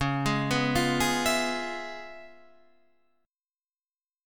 C#7#9 Chord